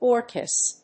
音節or・chis 発音記号・読み方
/ˈɔɚkɪs(米国英語), ˈɔːkɪs(英国英語)/